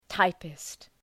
Προφορά
{‘taıpıst}